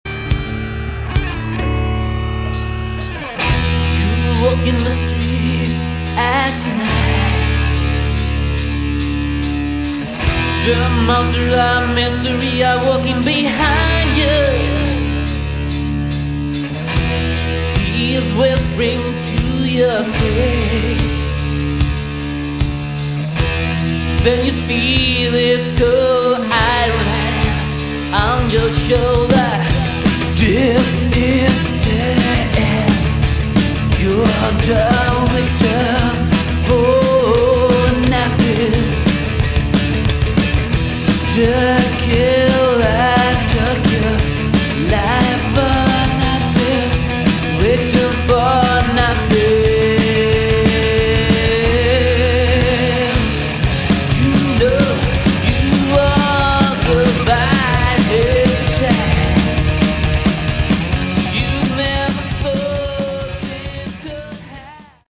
bass
guitars
vocals
drums
Recorded in 1993 and pressed as a 7-inch vinyl EP.